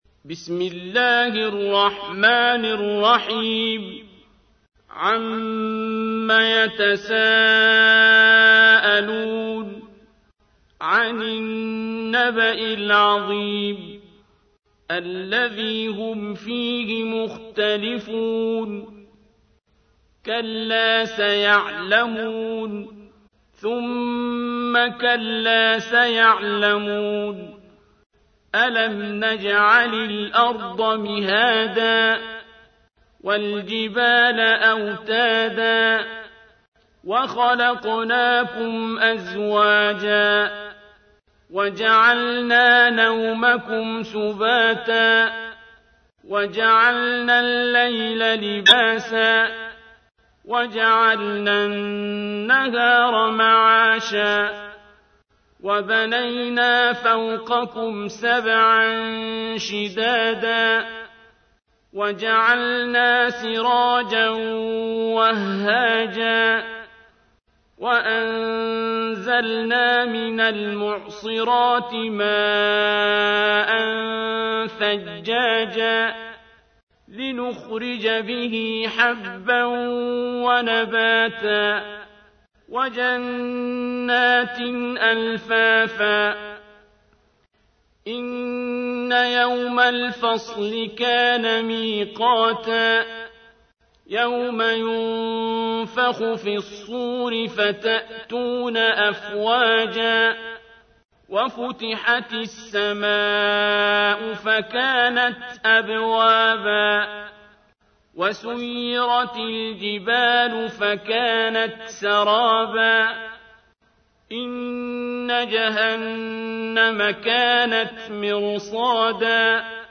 تحميل : 78. سورة النبأ / القارئ عبد الباسط عبد الصمد / القرآن الكريم / موقع يا حسين